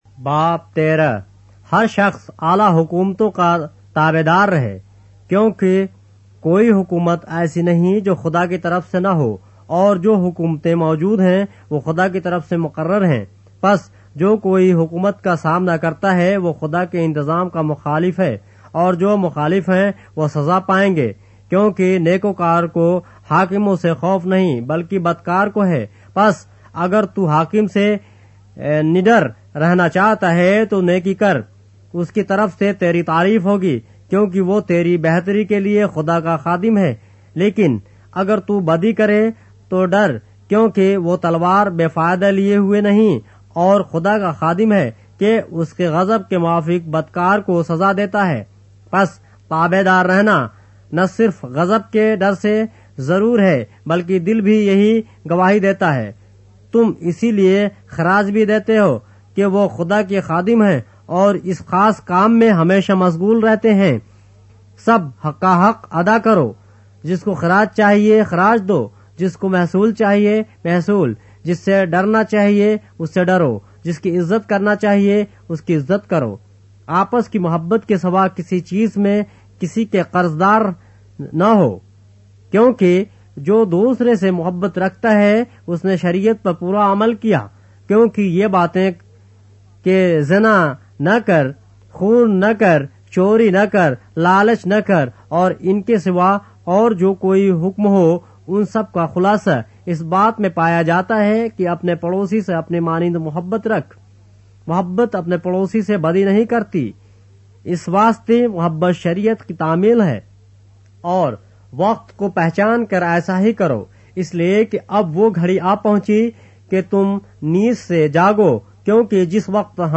اردو بائبل کے باب - آڈیو روایت کے ساتھ - Romans, chapter 13 of the Holy Bible in Urdu